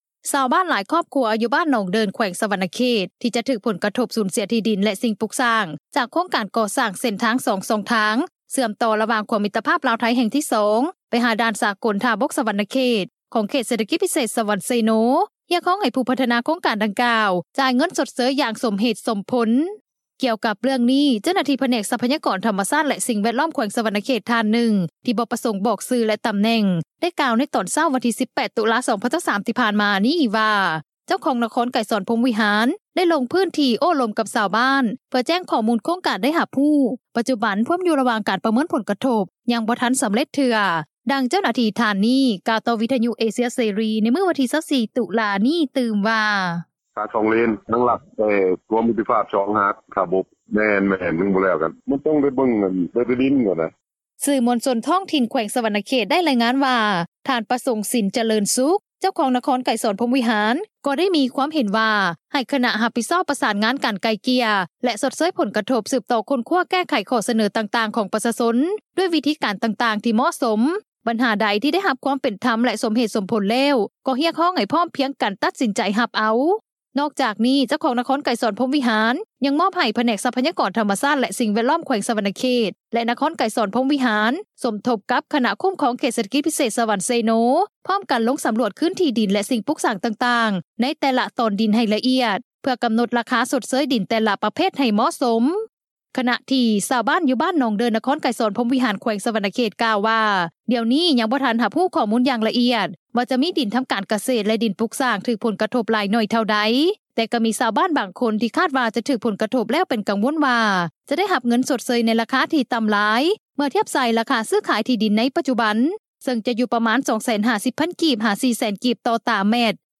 ດັ່ງເຈົ້າໜ້າທີ່ທ່ານນີ້ ກ່າວຕໍ່ວິທຍຸເອເຊັຽເສຣີ ໃນມື້ວັນທີ 24 ຕຸລາ ນີ້ ຕື່ມວ່າ: